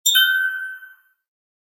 Neon Light Sound Effect
A clean neon light sound effect featuring soft blinking and a gentle turn-on sequence with a melodic tone. This subtle, musical blink captures the visual feel of a neon sign lighting up without electric buzzing.
Neon-light-sound-effect.mp3